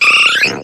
eevee_ambient.ogg